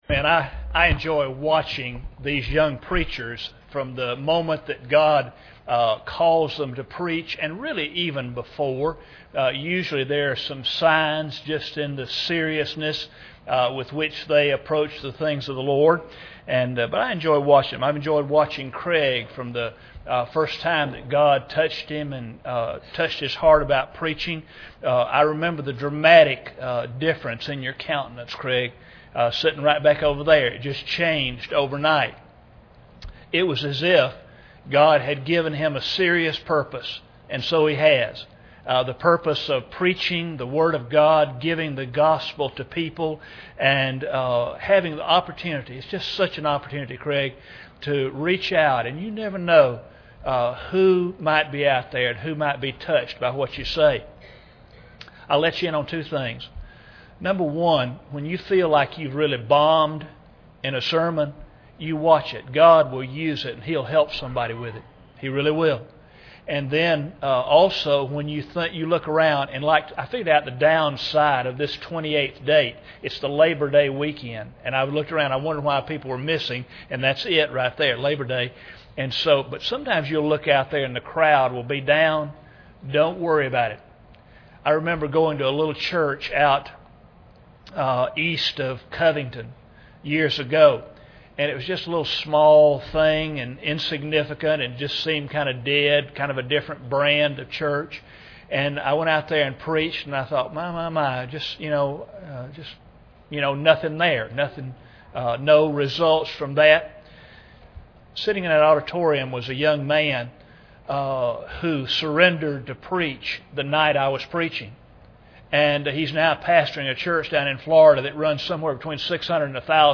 Haggai 1:1-14 Service Type: Wednesday Evening Bible Text